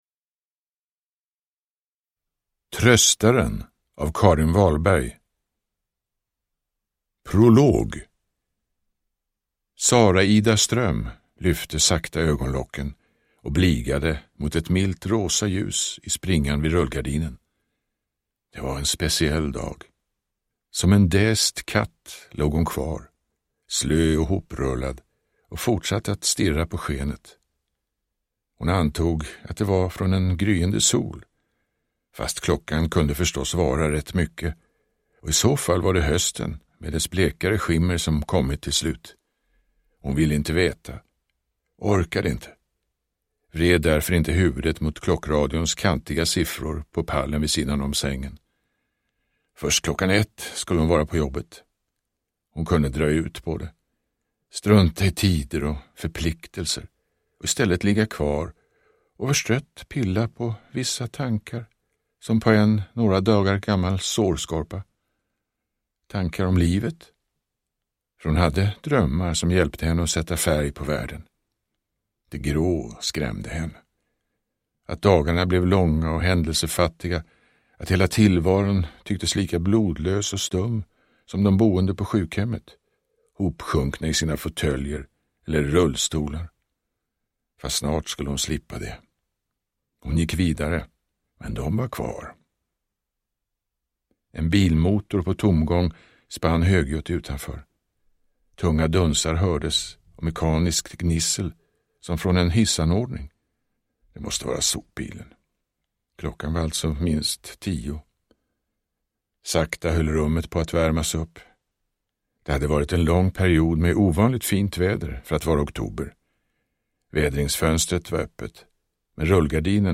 Uppläsare: Torsten Wahlund
Ljudbok